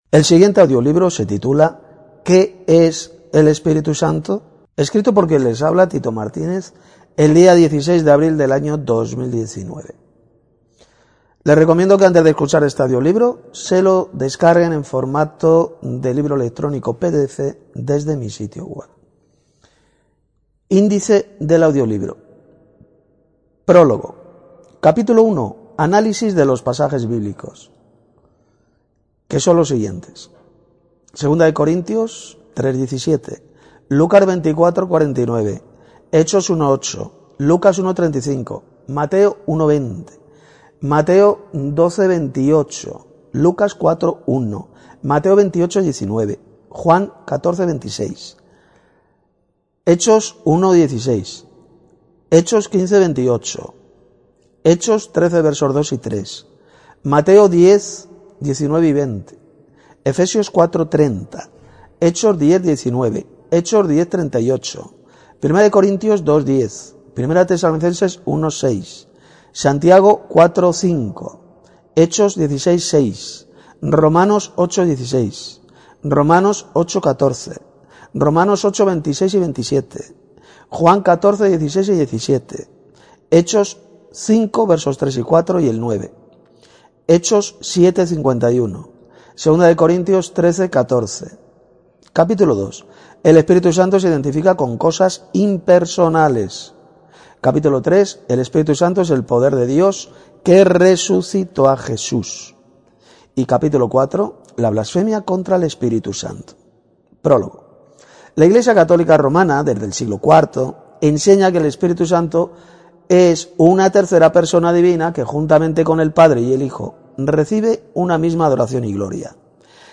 Para escuchar este libro en audio mp3: